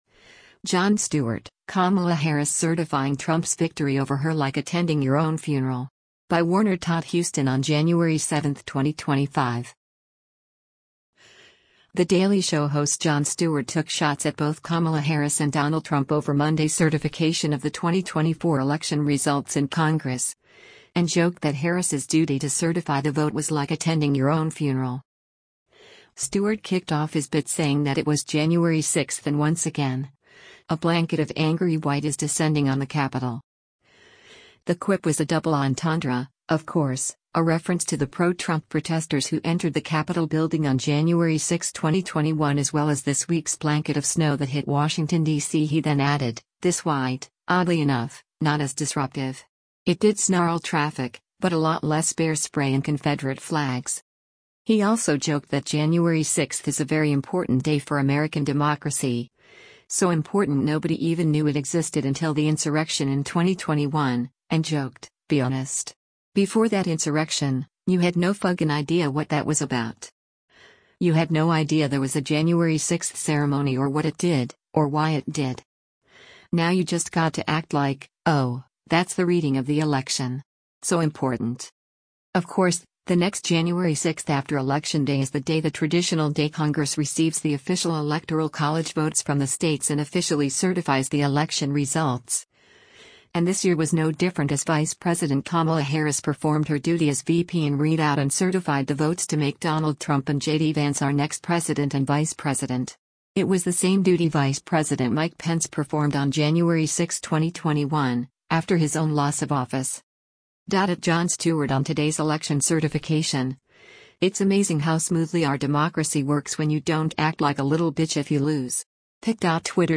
Stewart went on to play the clip of Harris reading out Trump’s numbers which elicited a loud round of applause from the Republican majority, according to Variety.
But Harris also got applause when she read out her own vote totals, too.
“Wait! That sounded louder,” Stewart said sarcastically.